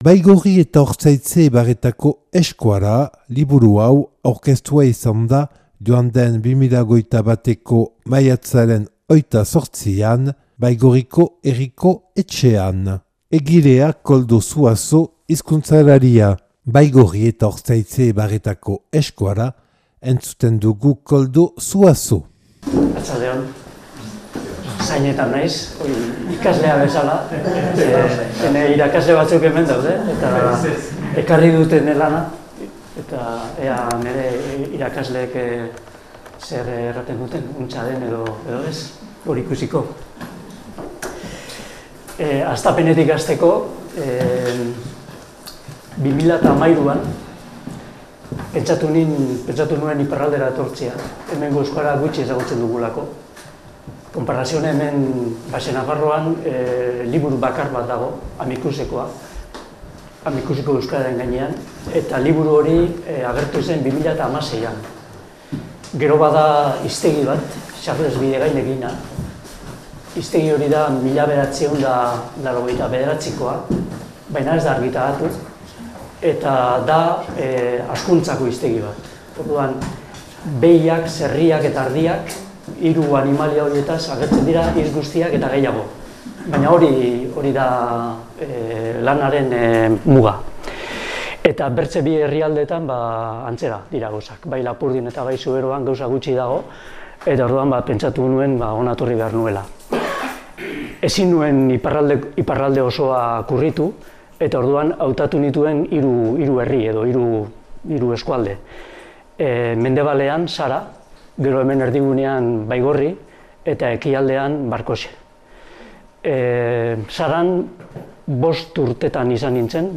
(Baigorriko Herriko Etxean grabaua 2021. maiatzaren 28an).